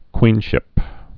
(kwēnshĭp)